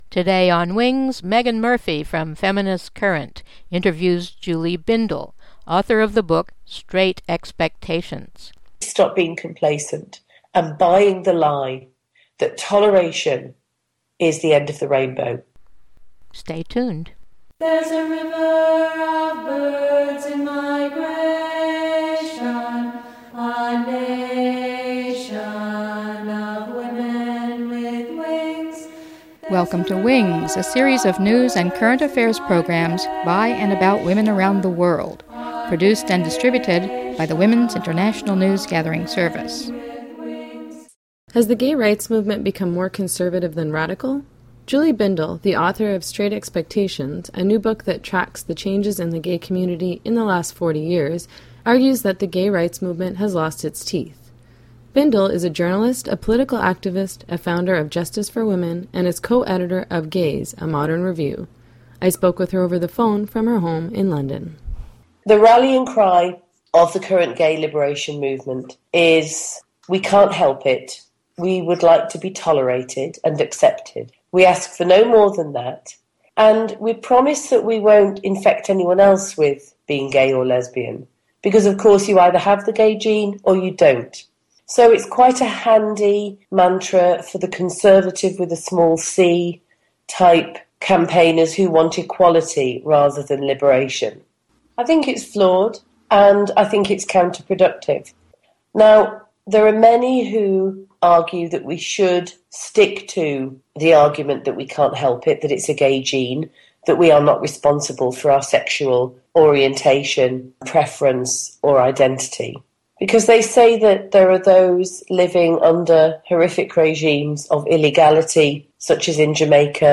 File Information Listen (h:mm:ss) 0:28:50 WINGS29-14StraightExpectations-28_50-192kbps.mp3 Download (38) WINGS29-14StraightExpectations-28_50-192kbps.mp3 41,514k 192kbps Mono Comments: Skype interview sound.